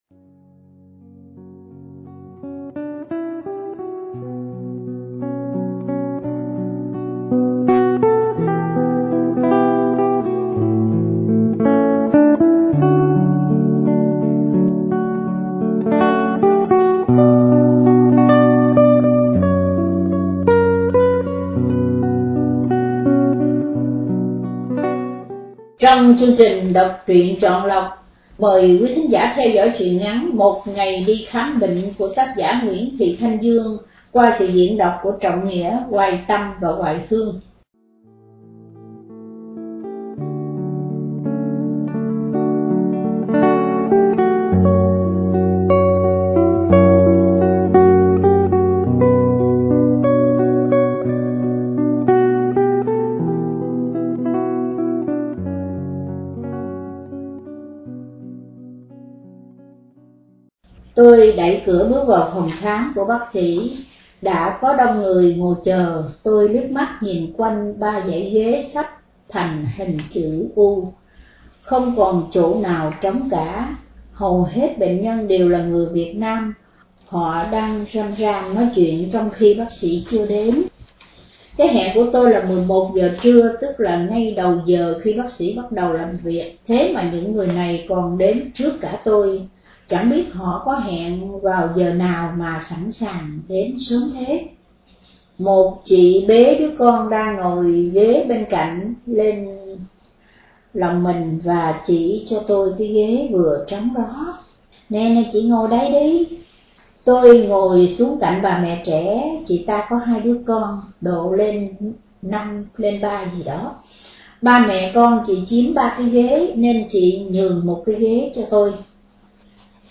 Đọc Truyện Chọn Lọc